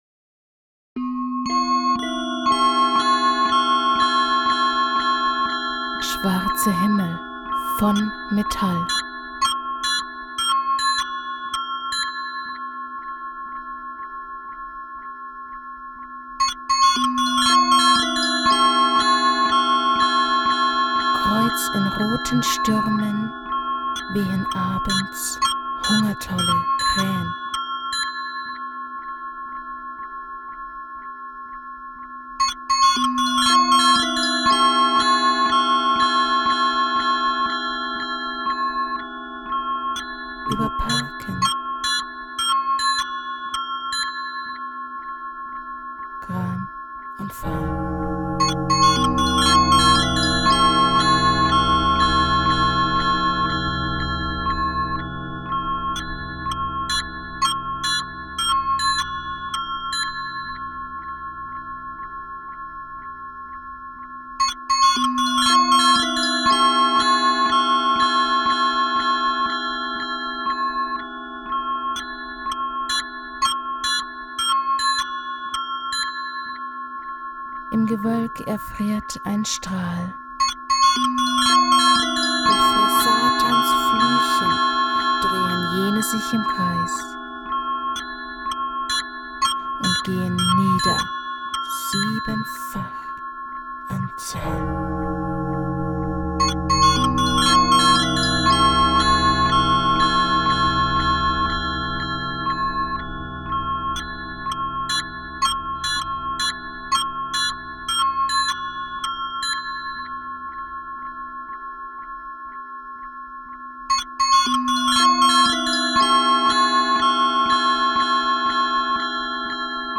Meine Aufgabe bestand darin, die Gedichte auf verschiedenste Art und Weise einzusprechen.